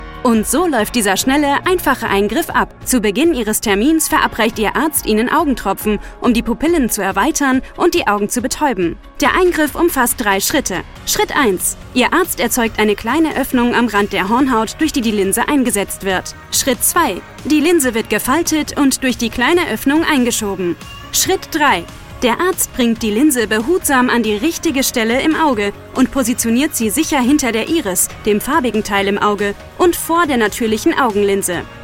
Young, Natural, Playful, Accessible, Friendly
Explainer